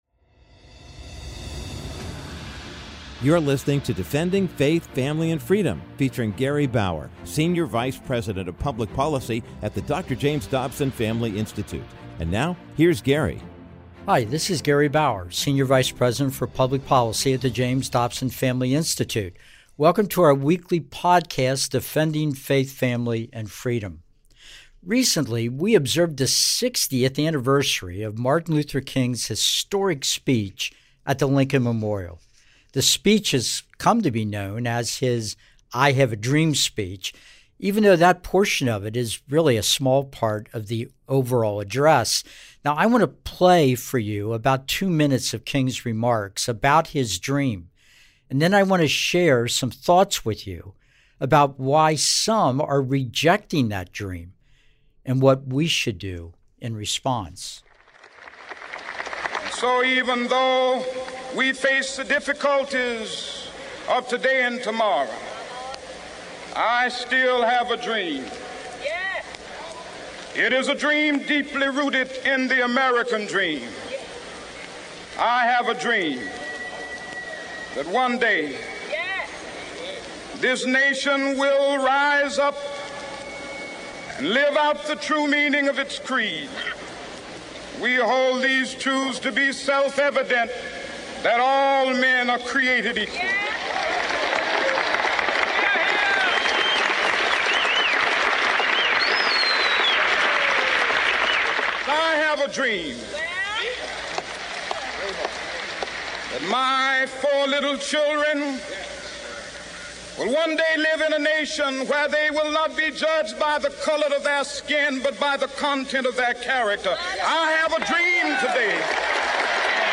In this week's episode of Defending Faith, Family, and Freedom with Gary Bauer, senior vice president of public policy at the James Dobson Family Institute shares audio from the famous “I Have a Dream” section of Martin Luther King’s speech from the Washington, D.C. Mall, delivered 60 years ago. Bauer adds colorful commentary and incredible behind-the-scenes details surrounding King’s unforgettable remarks—many of which you may have never heard.